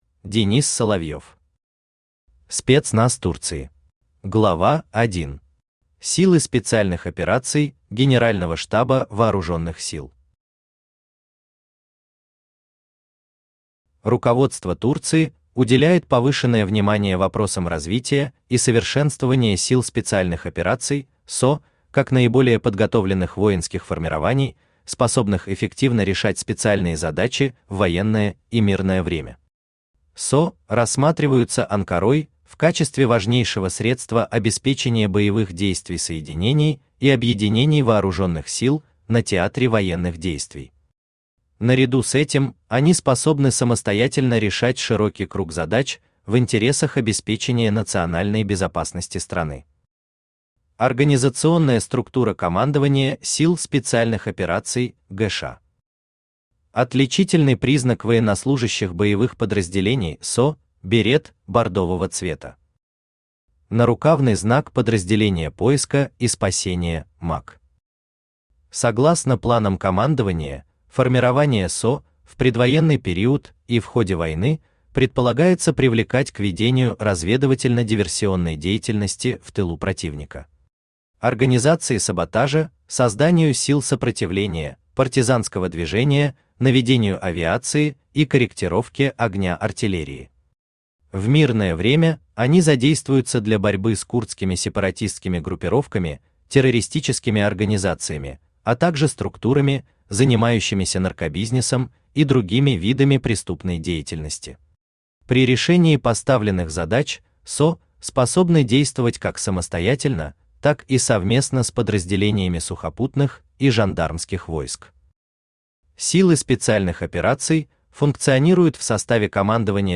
Аудиокнига Спецназ Турции